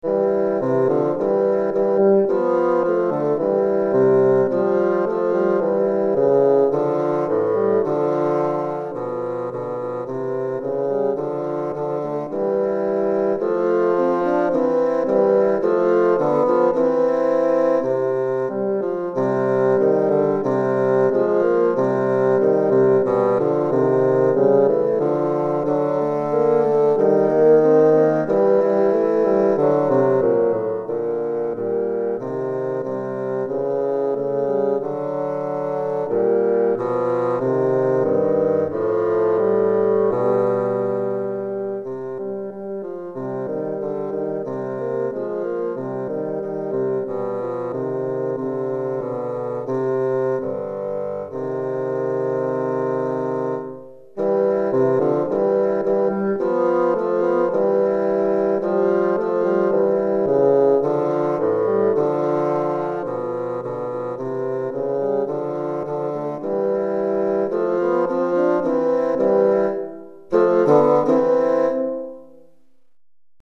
Pour 2 bassons DEGRE fin de cycle 1 Durée